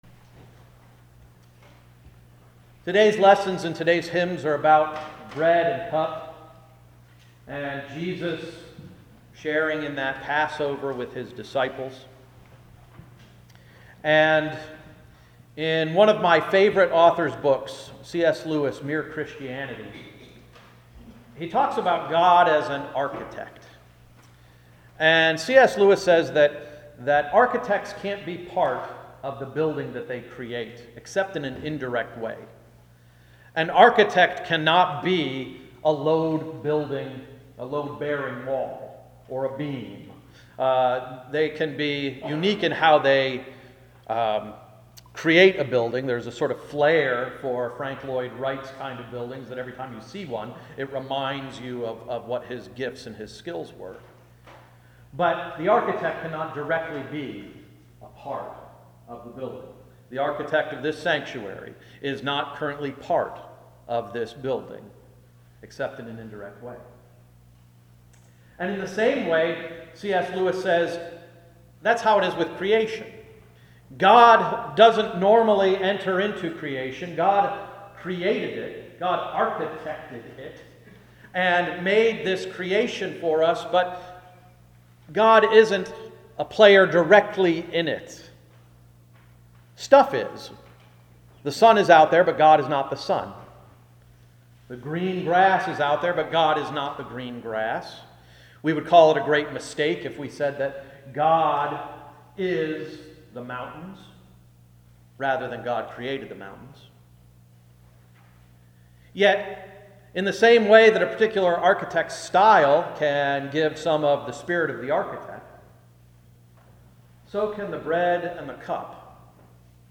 Welcome to Hill's Church Export, PA